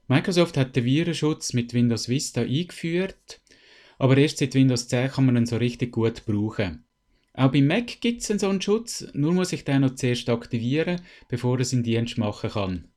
Dieses Interview gibt es auch auf Hochdeutsch!!